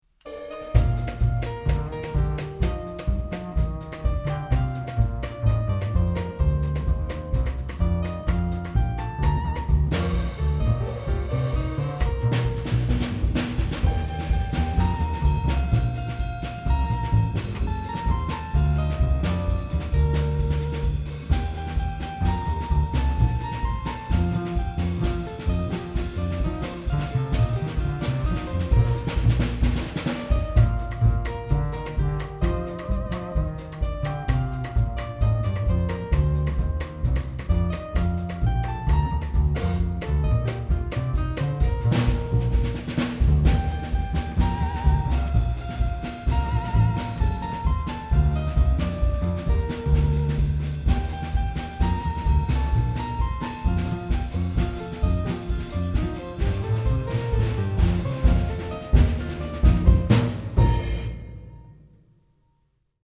L’ébouriffante croisière est faite de mots, de sons et de musiques. Sur scène sans artifice, le narrateur déroule le récit en complicité avec les trois musiciens, qui participent à l’action par le jeu instrumental et quelques paroles pertinentes et impertinentes.
contrebasse, voix
claviers
percussions